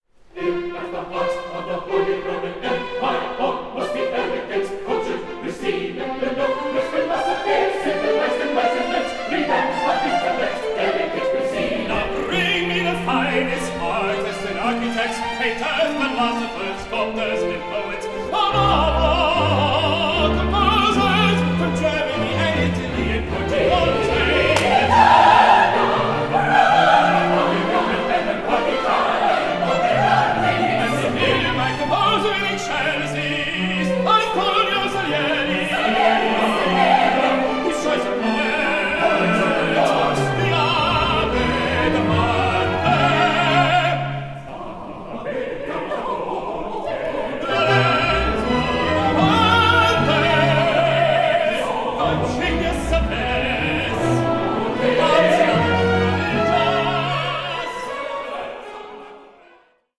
Aria interrotta